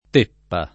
teppa [ t % ppa ] s. f.